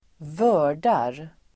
Ladda ner uttalet
Uttal: [²v'ö:r_dar]